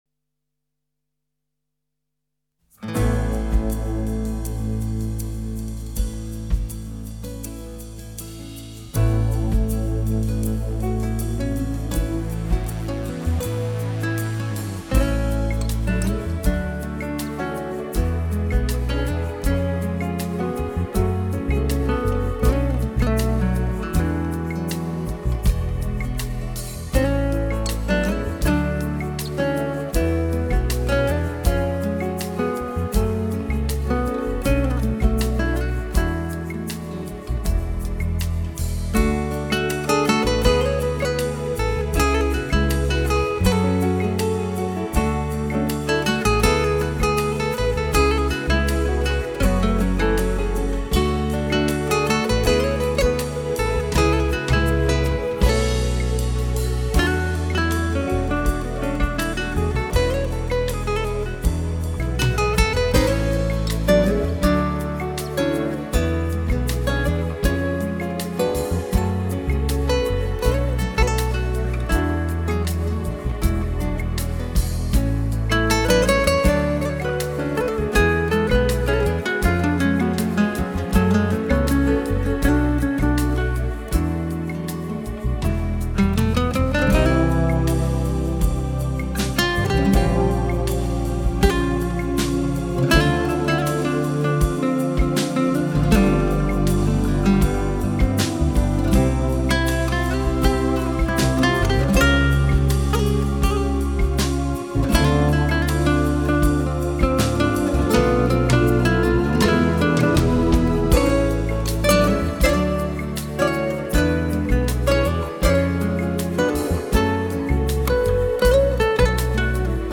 力（鼓声）与美（吉他）的完美结合